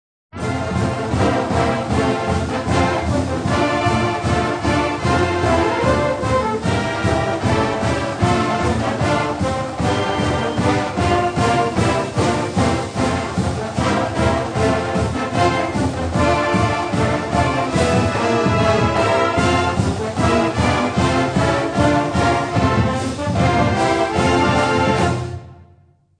Since most theme music for colleges orginated in the 1920s and 30s, the sound is a traditional one with modernist tweaks: military band arrangements with ragtimey accent, typically played very quickly, often ripped through at lightspeed following big plays in games.
“Fight CU” comes dangerously close to a dirge, and Ohio State gets relegated to the “Dullards” category for having not one, but two tepid fight songs.